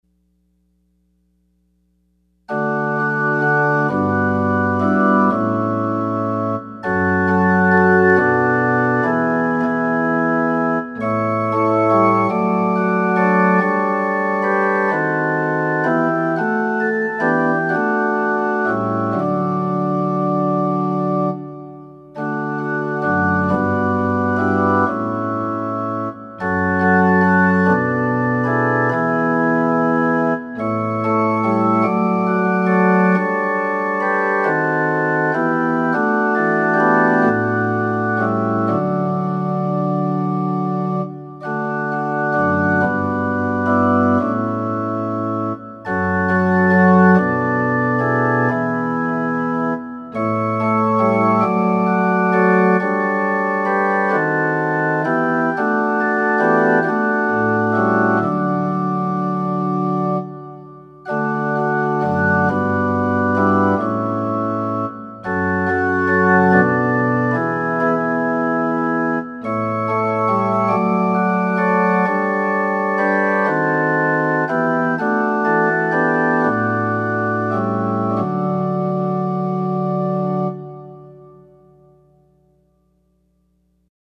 Offering Hymn – Breathe on me, breath of God #508